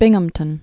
(bĭngəm-tən)